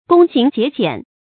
躬行节俭 gōng xíng jié jiǎn
躬行节俭发音